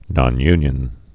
(nŏn-ynyən)